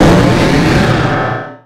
Cri de Méga-Léviator dans Pokémon X et Y.
Cri_0130_Méga_XY.ogg